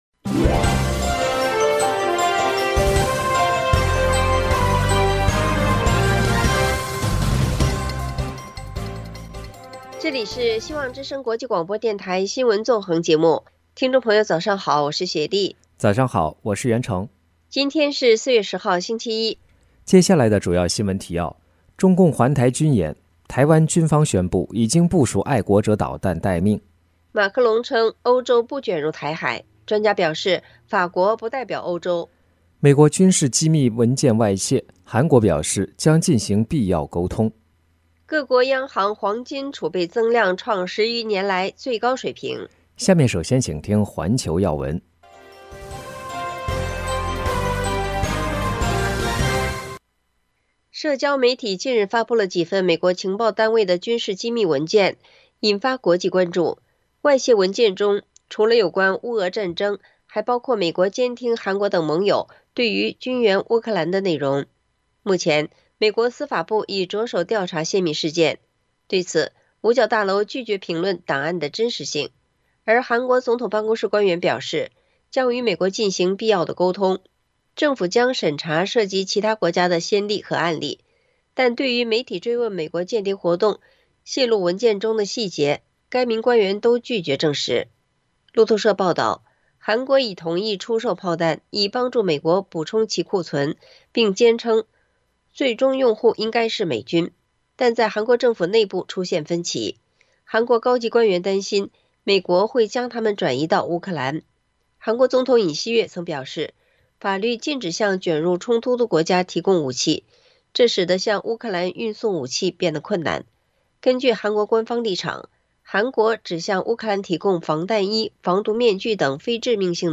新聞提要（上半場） 環球新聞 1、美国军